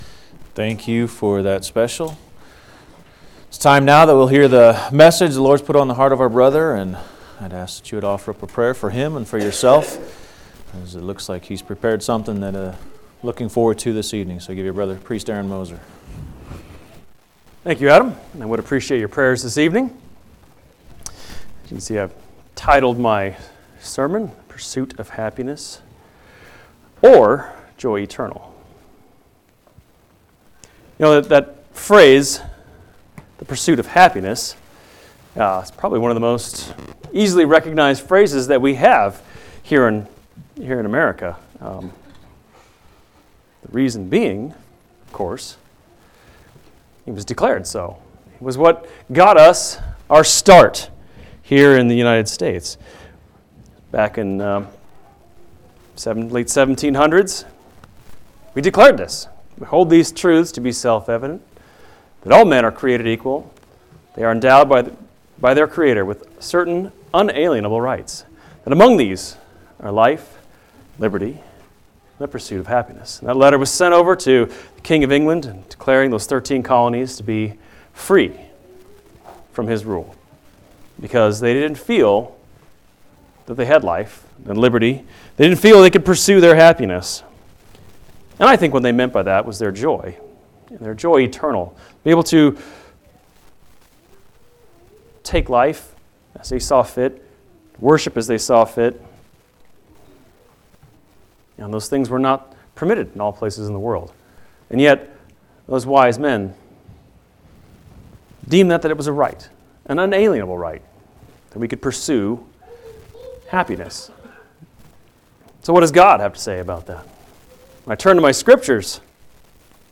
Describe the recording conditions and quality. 12/2/2018 Location: Phoenix Local Event